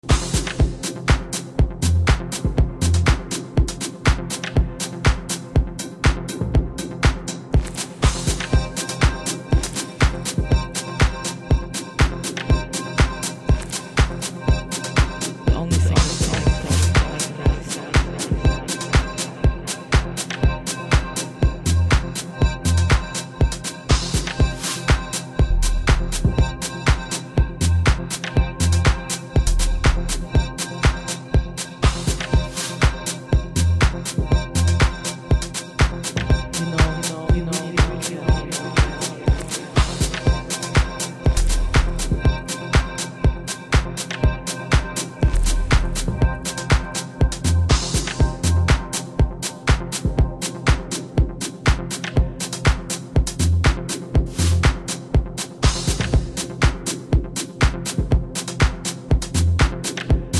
The long awaited album full of elegant deep house tracks.